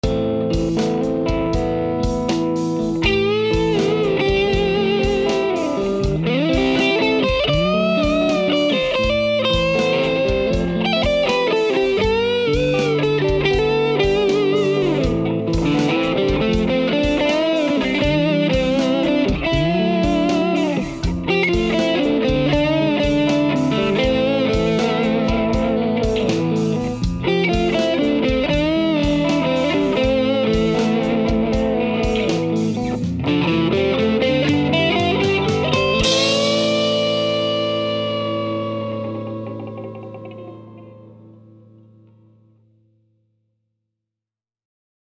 In this final clip, I feature the guitar alone.
The end result isn’t a bad sound at all, but I really had to work hard to get some sustain out of the guitar, and instead of just using the natural drive of my amp, I opted to run the guitar through my Tube Screamer and a touch of compression to add some sustain and give the signal some balls. Also, I played this clip with the neck pickup of the guitar, as the bridge pickup pretty much did nothing for me.